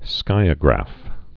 (skīə-grăf)